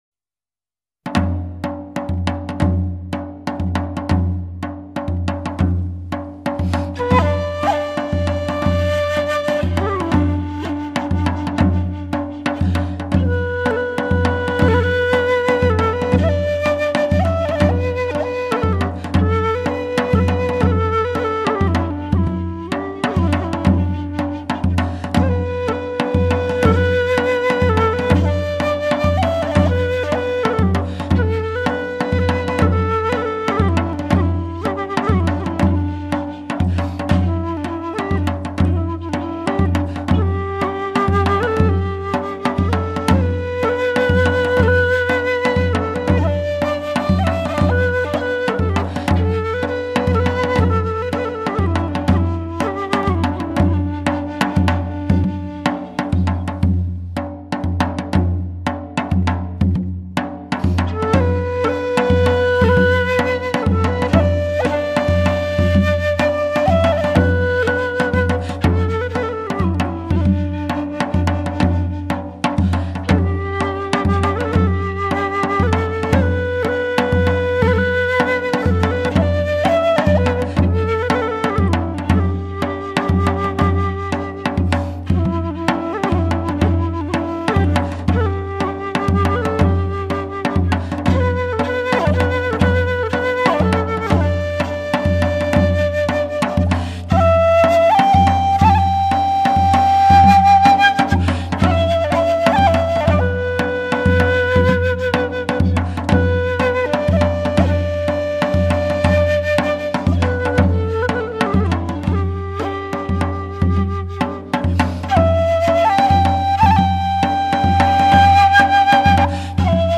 全面体现鼓弦交织的完美魅力
它频响宽阔，尤其是那火爆的动感与深不见底的低频，更是检测器材之参考利器。
动中有静，刚中带柔，正是本专辑之引人之处。